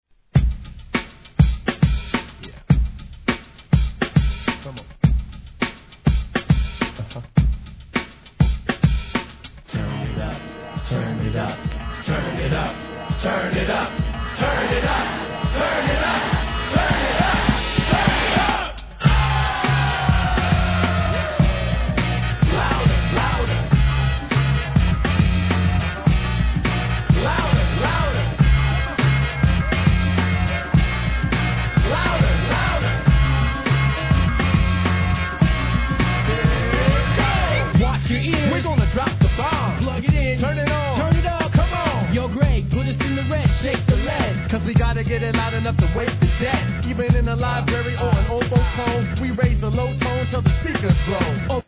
HIP HOP/R&B
イントロからテンション↑↑なブレイクにCOOLなホーン!